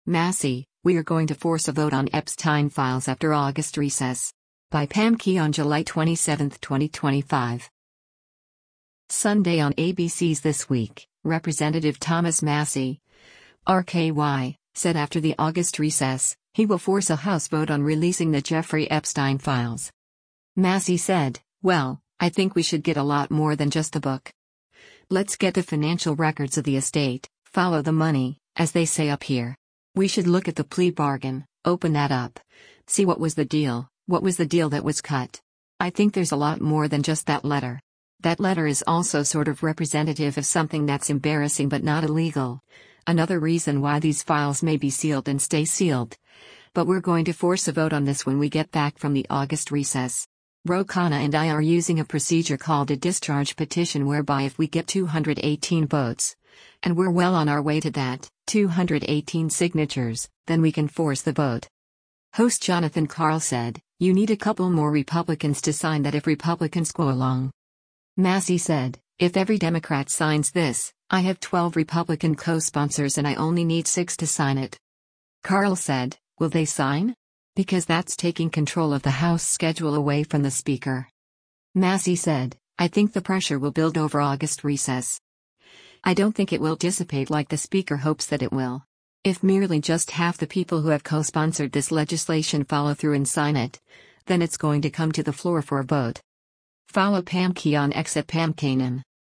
Sunday on ABC’s “This Week,” Rep. Thomas Massie (R-KY) said after the August recess, he will force a House vote on releasing the Jeffrey Epstein files.